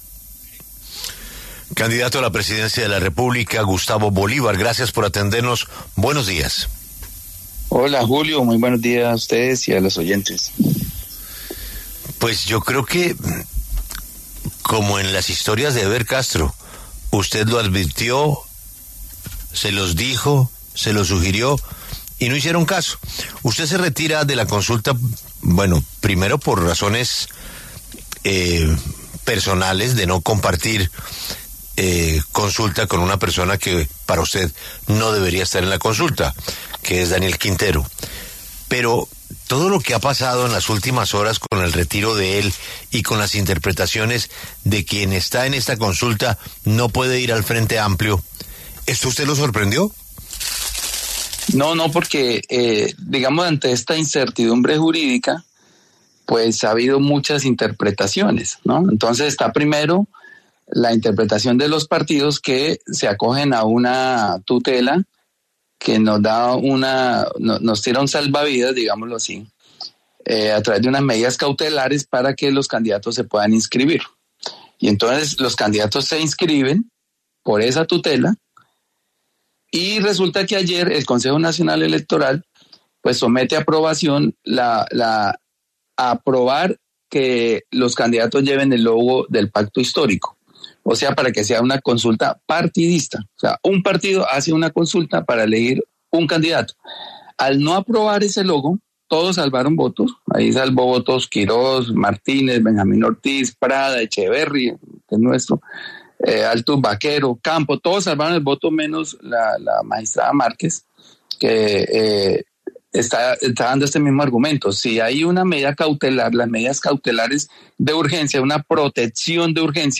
Gustavo Bolívar, exsenador y quien respalda a Iván Cepeda en la contienda por la Presidencia, pasó por los micrófonos de La W.